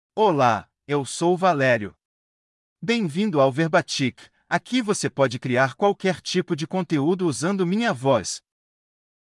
Valerio — Male Portuguese (Brazil) AI Voice | TTS, Voice Cloning & Video | Verbatik AI
ValerioMale Portuguese AI voice
Valerio is a male AI voice for Portuguese (Brazil).
Voice sample
Valerio delivers clear pronunciation with authentic Brazil Portuguese intonation, making your content sound professionally produced.